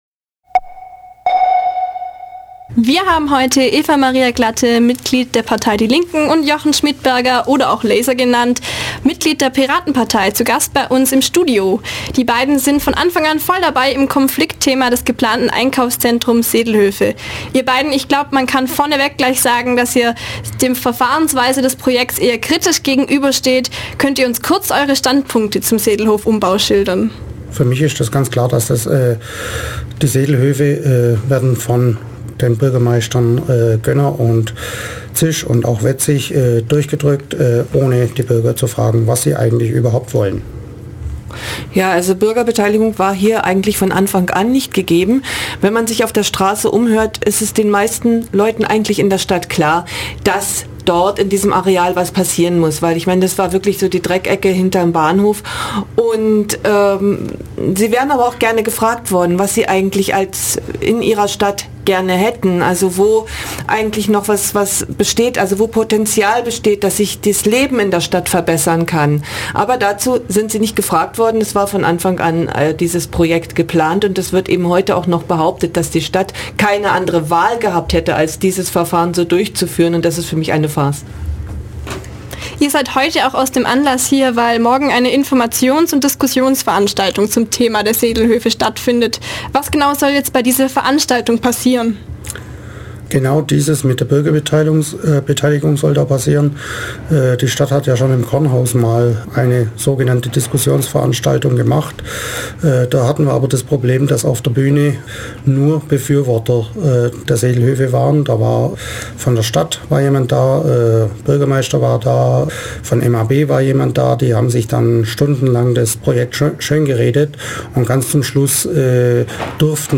interview_sedelhoefe_informationsveranstaltung.mp3